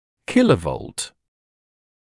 [‘kɪləvɔlt][‘килэволт]киловольт